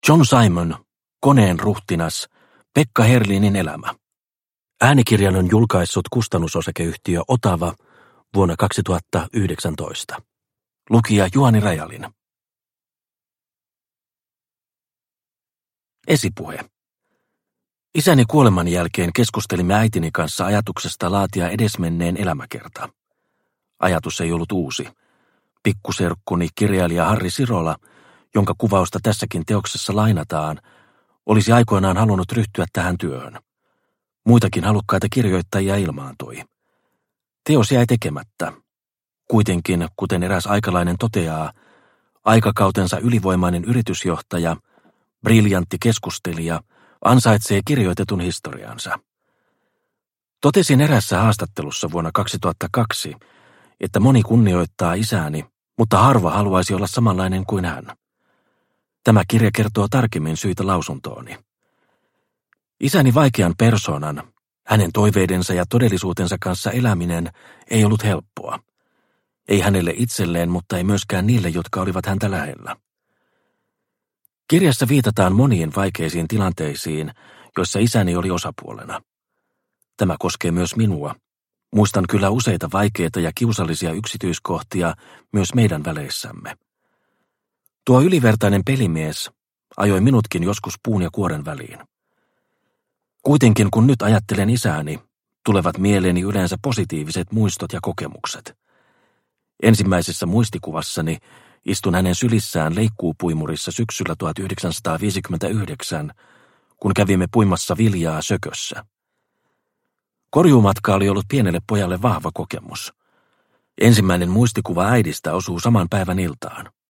Koneen ruhtinas – Ljudbok – Laddas ner